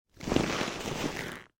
В кожаных перчатках подняли увесистую сумку